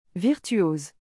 It's followed by a "z" soundclosed "o"